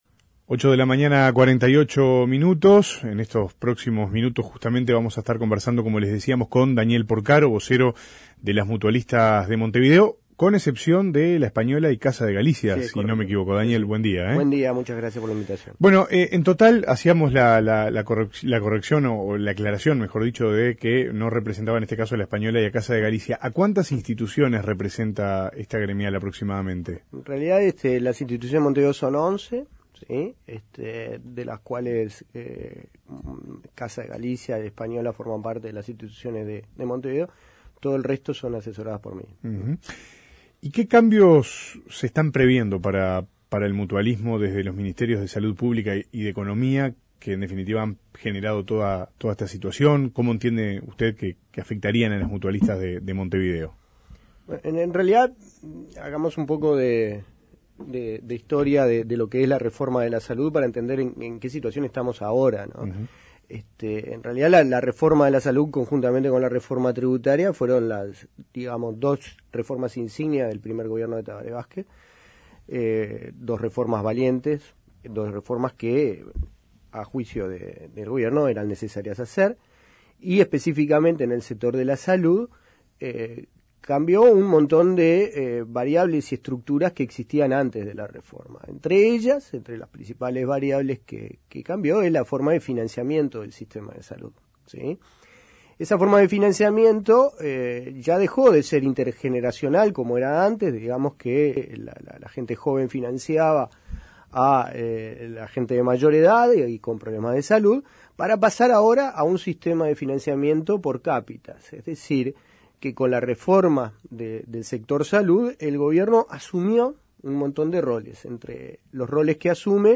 Escuche la entrevista completa aquí: Descargar Audio no soportado